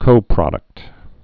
(kōprŏdəkt)